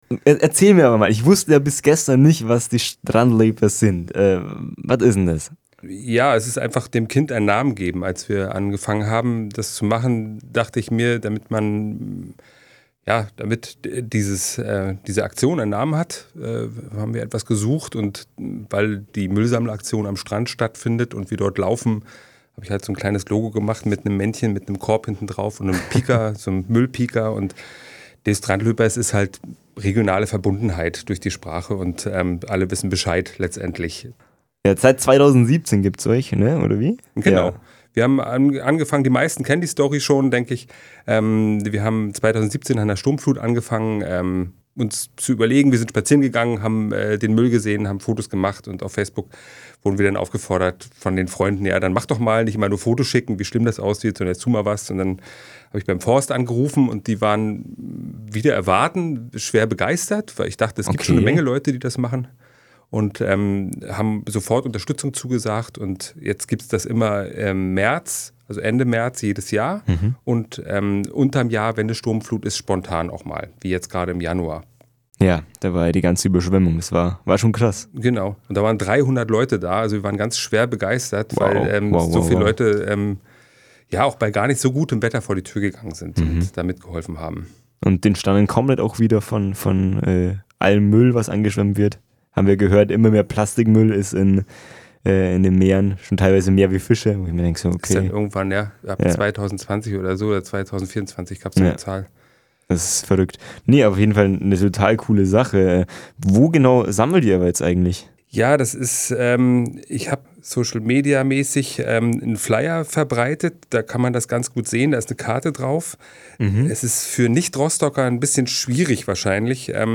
März – Studiogespräch im Weckruf